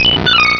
Cri de Marill dans Pokémon Rubis et Saphir.